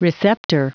Prononciation du mot receptor en anglais (fichier audio)
Prononciation du mot : receptor